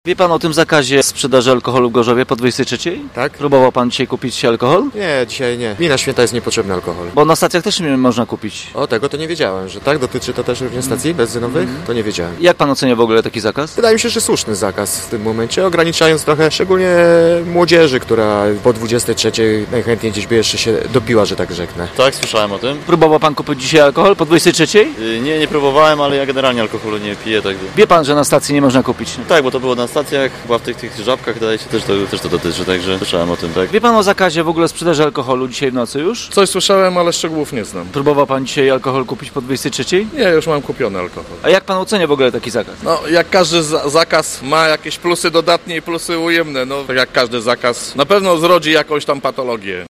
Dziś pytaliśmy gorzowian, co sądzą o nocnej prohibicji w mieście.